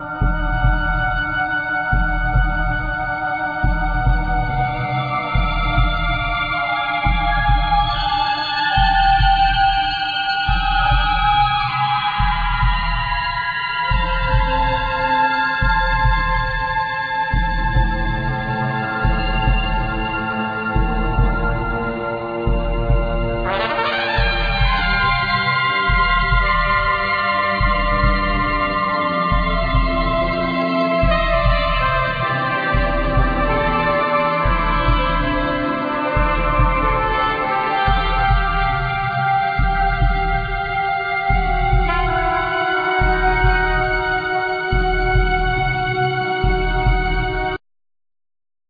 Violin
Viola
Cello
Trumpet
Sax
Clarinet
Piano
Flute
Guitar
Computers,Synthsizers